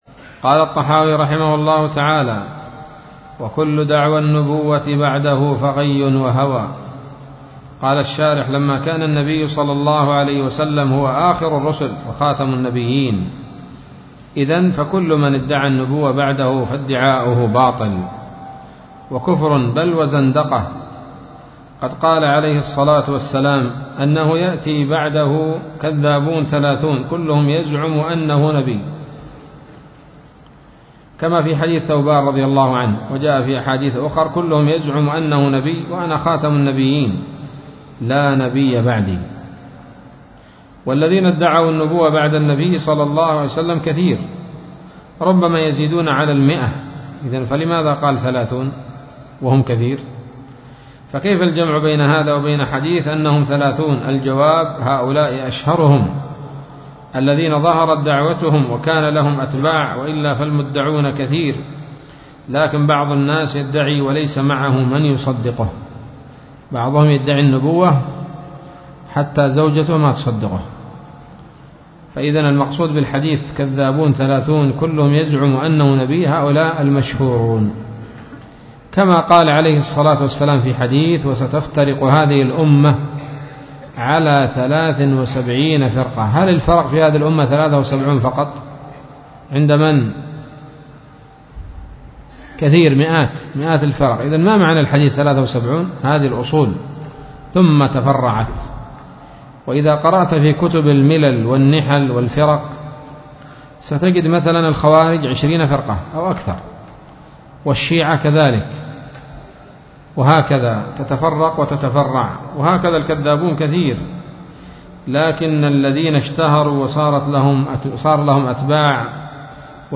الدرس الثالث والعشرون